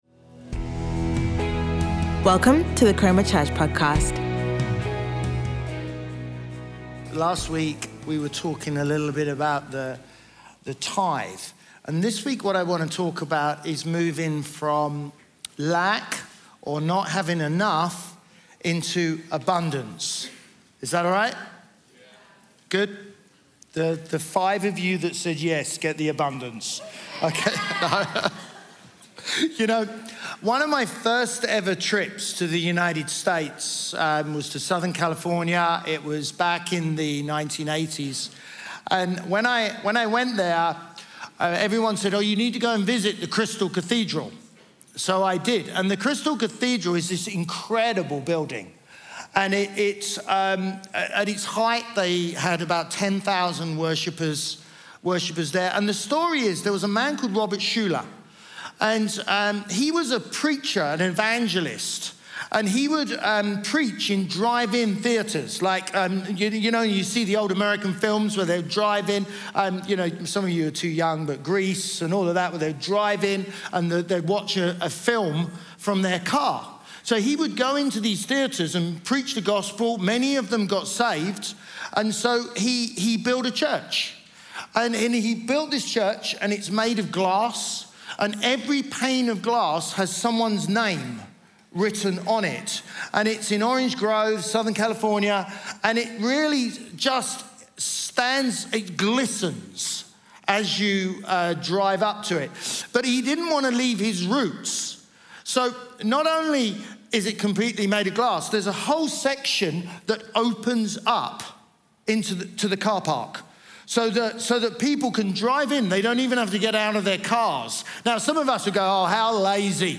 Chroma Church - Sunday Sermon Moving from Lack to Abundance